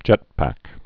(jĕtpăk)